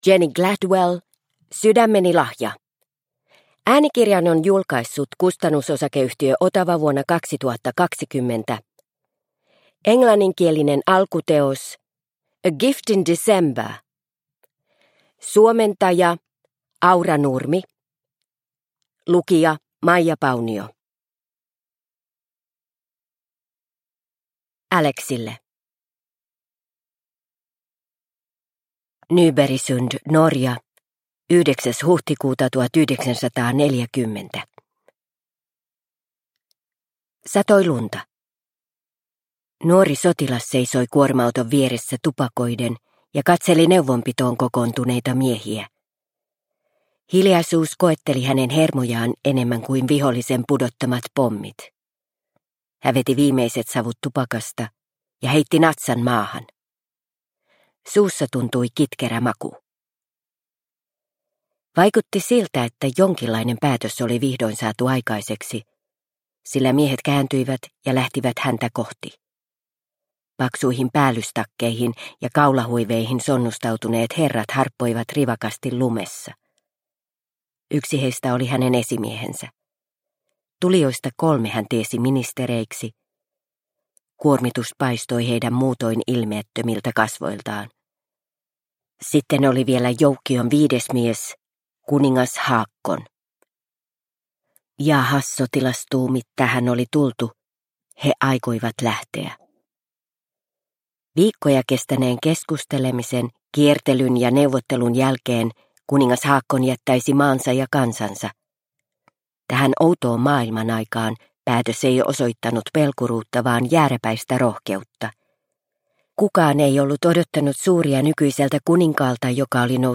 Sydämeni lahja – Ljudbok – Laddas ner